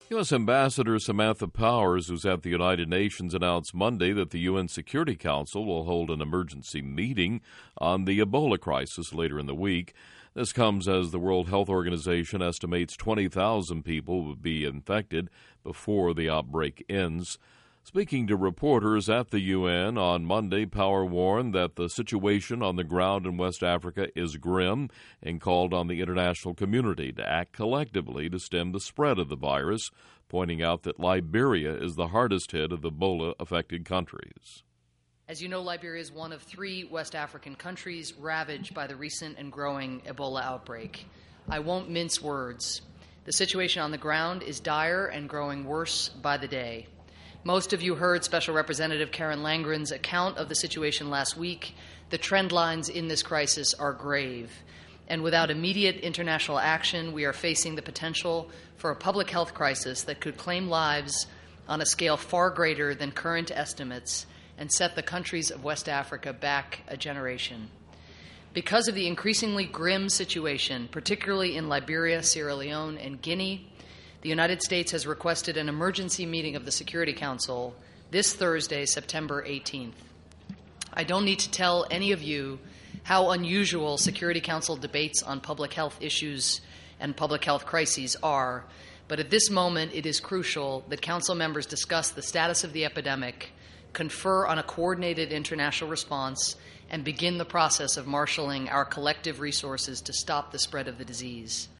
Speaking To Reporters At The UN, Power Warned That The Situation On The Ground In West Africa Is Grim And Called On The International Community To Act Collectively To Stem The Spread Of The Virus.